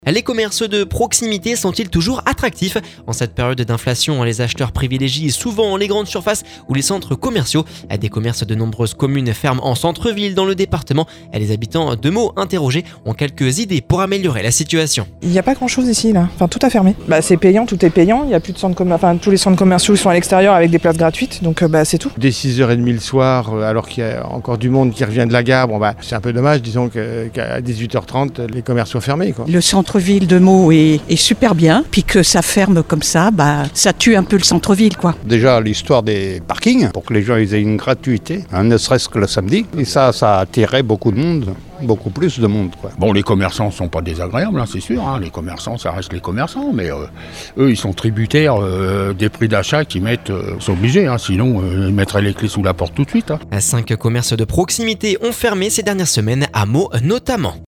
En cette période d’inflation, les acheteurs privilégient souvent les grandes surfaces ou les centres commerciaux. Des commerces de nombreuses communes ferment en centre-villes dans le département. Les habitants de Meaux intérrogés ont quelques idées pour améliorer la situation…